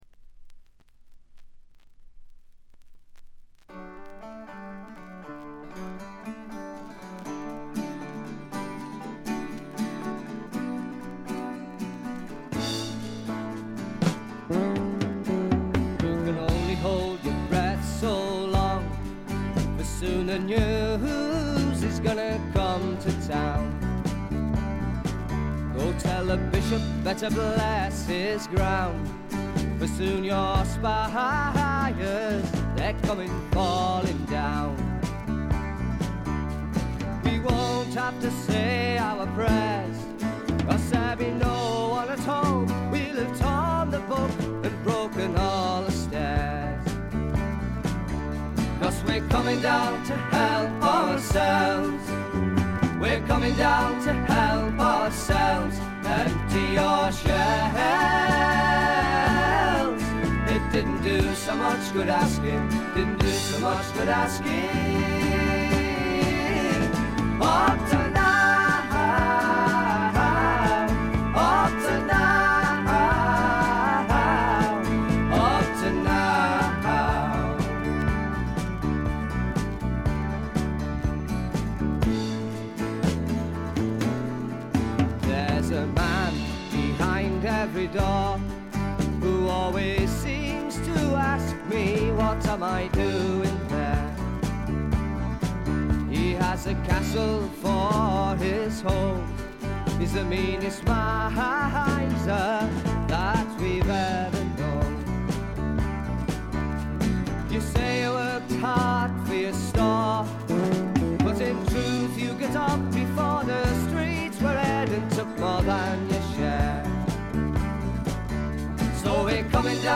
ところどころでチリプチ。散発的なプツ音が3回ほど。
エレクトリック・トラッド（オリジナル曲だけど）のお手本といえるほどの素晴らしい出来ばえです。
端正でびしっと決まる演奏はバンド形態ならではのもの。
試聴曲は現品からの取り込み音源です。